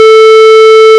# Create synthesized harmonic tone on A4 = 440